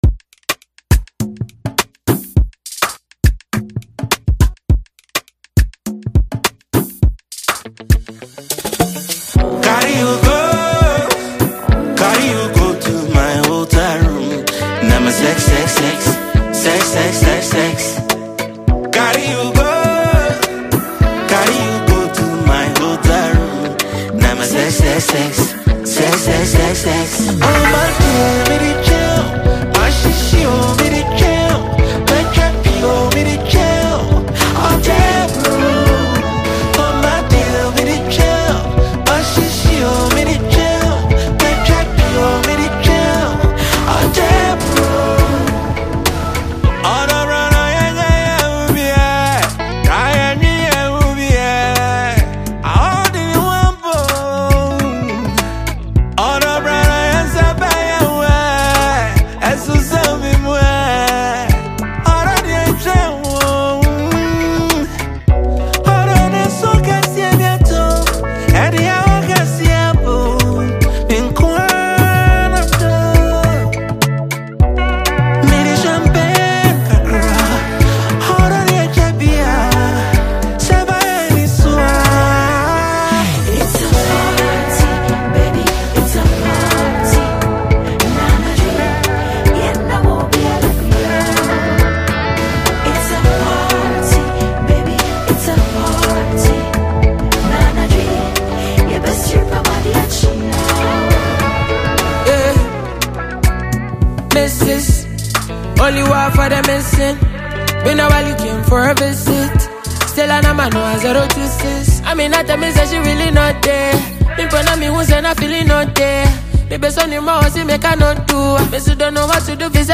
Genre: Highlife / Afro-Fusion